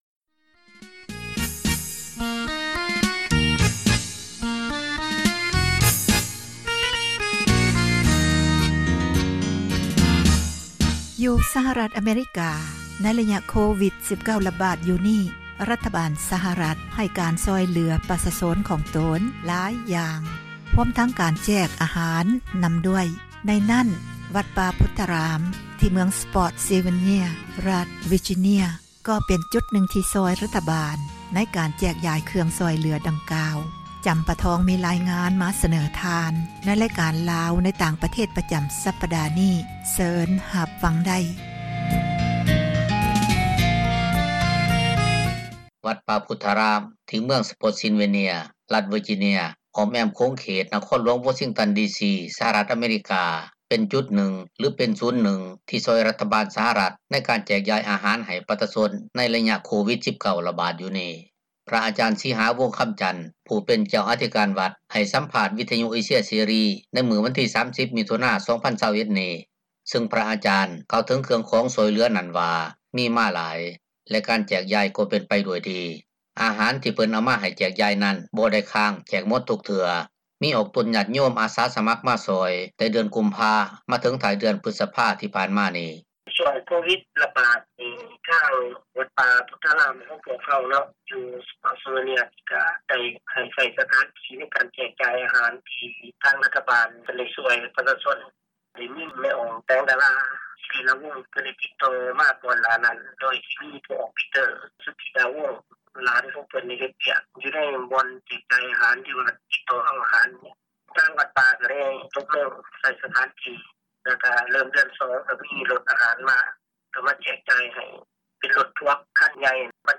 ໃຫ້ສັມພາດ ວິທຍຸເອເຊັຽເສຣີ